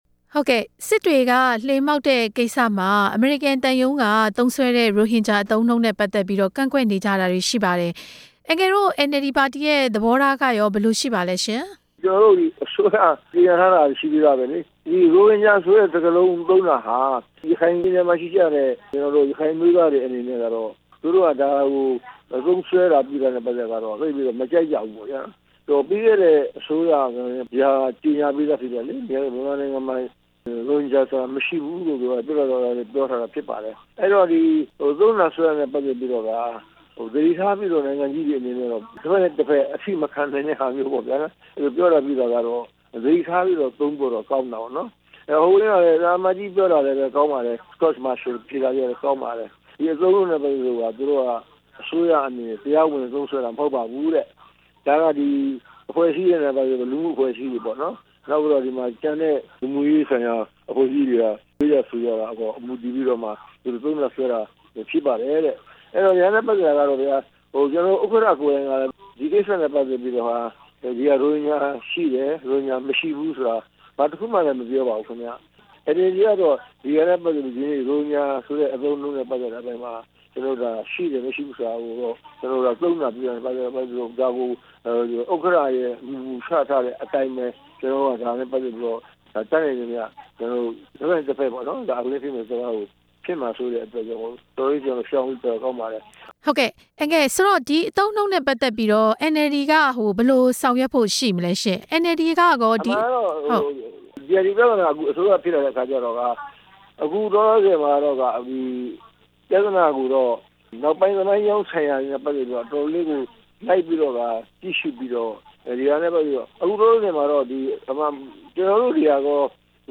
အမေရိကန်သံရုံးရဲ့ ရိုဟင်ဂျာအသုံးအနှုန်း၊ NLD ဦးတင်ဦးနဲ့ မေးမြန်းချက်